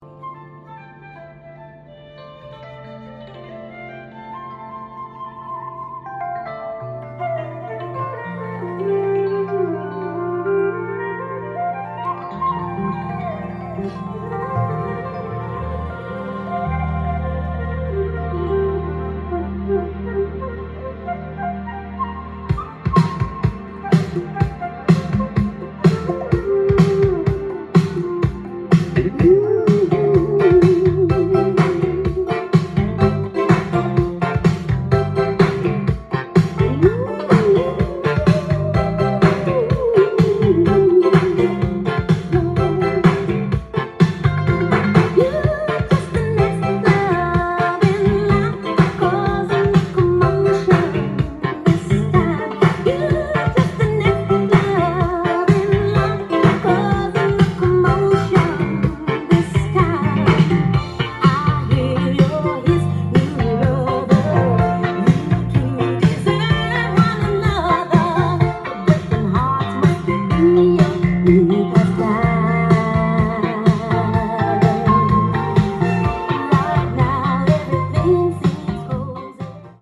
soul120
店頭で録音した音源の為、多少の外部音や音質の悪さはございますが、サンプルとしてご視聴ください。